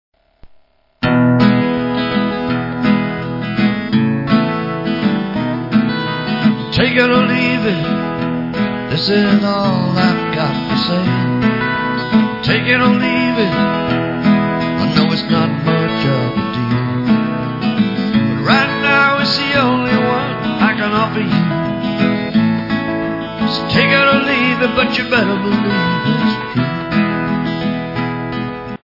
LIVE PERFORMANCE SECTION
ACOUSTIC GUITAR